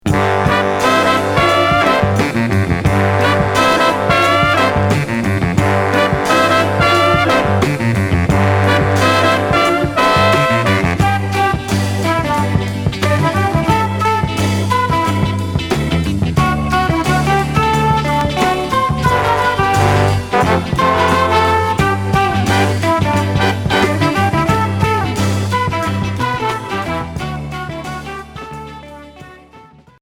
Groove